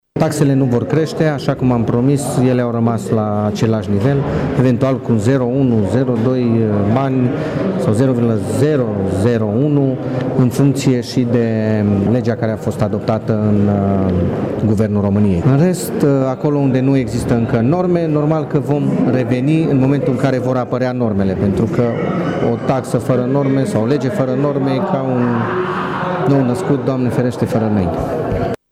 Executivul Primăriei va reveni cu un proiect în Consiliu după ce aceste norme vor apărea, spune viceprimarul Claudiu Maior: